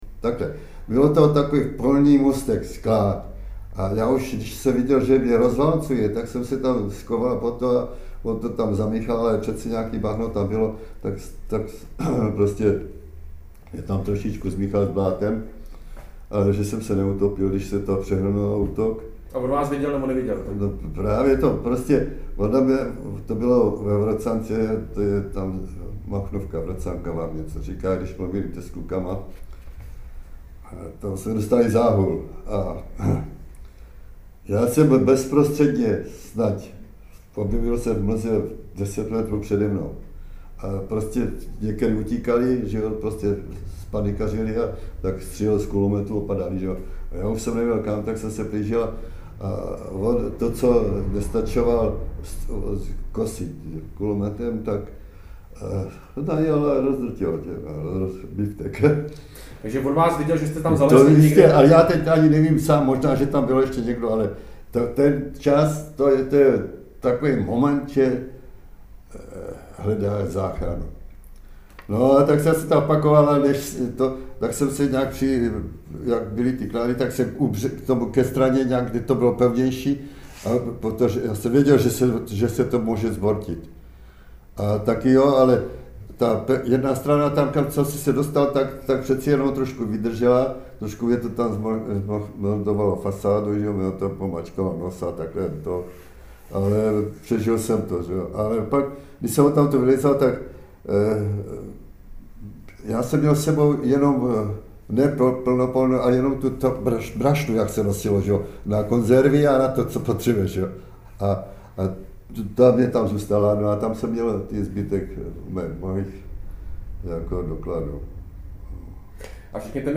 natočený v rámci vyprávění